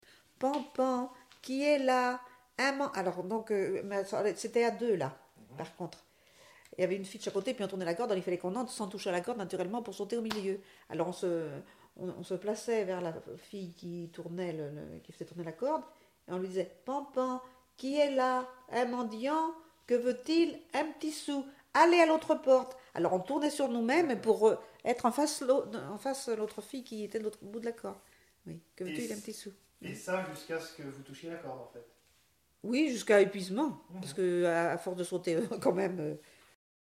enfantine : jeu de corde
Témoignage et chansons
Pièce musicale inédite